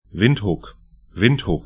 Pronunciation
Windhuk 'vɪnthʊk Windhoek 'vɪnthʊk af Stadt / town 22°30'S, 17°00'E